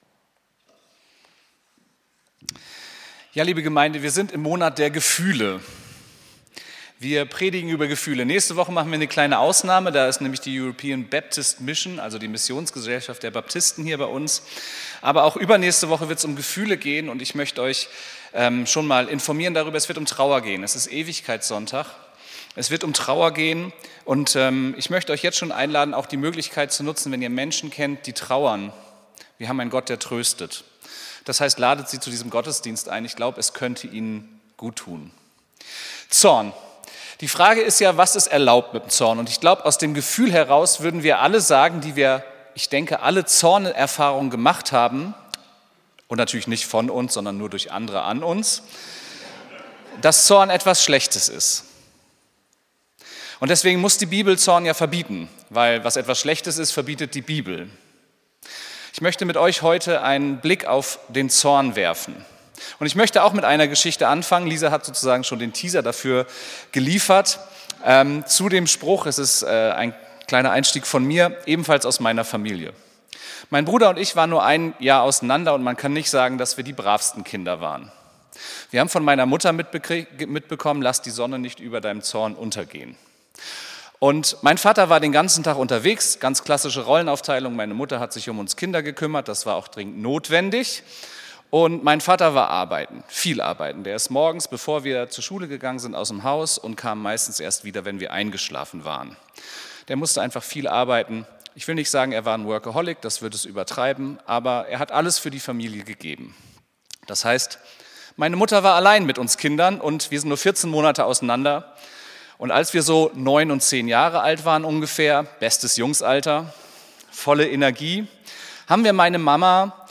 Predigt vom 09.11.2025